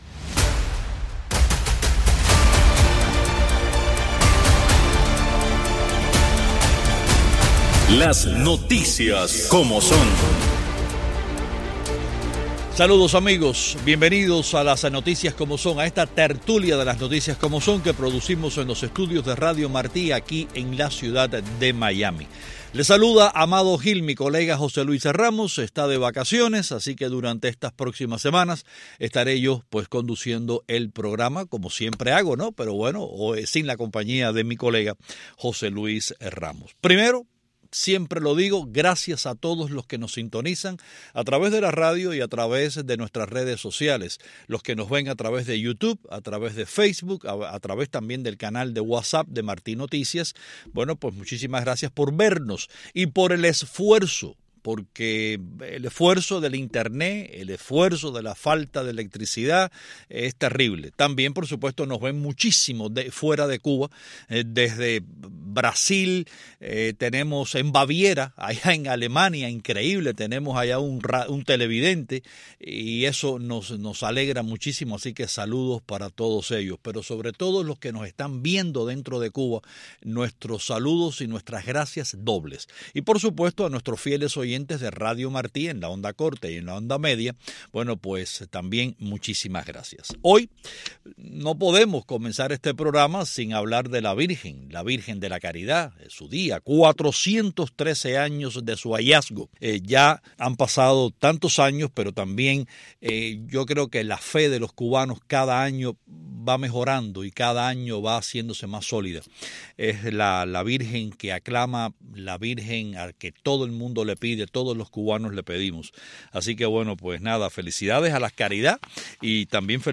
Hoy, en la Tertulia de Las Noticias Como Son: | Día de la Caridad | Regresa a Cuba Díaz-Canel de su gira por Asia con excelentes intercambios de halagos | Oriente se apagó durante el fin de semana | Muere trabajador de Renté | Dólar y Euro no ceden.